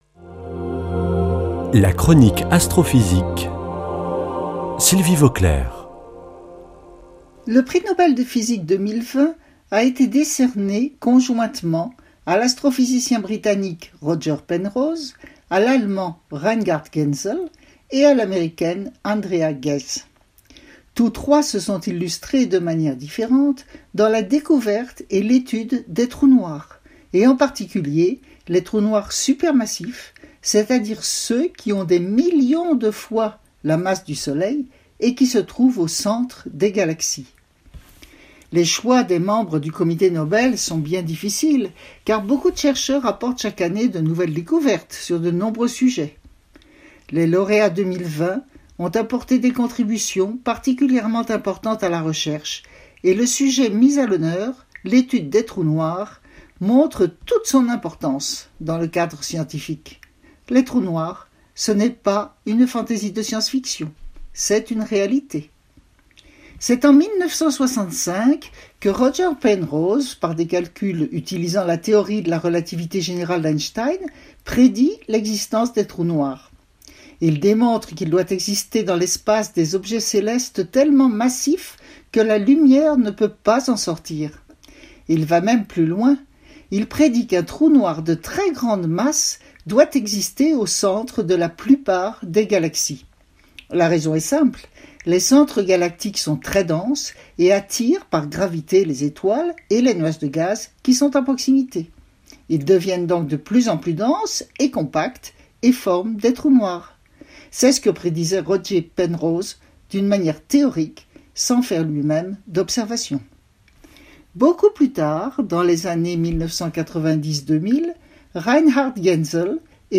lundi 26 octobre 2020 Chronique Astrophysique Durée 3 min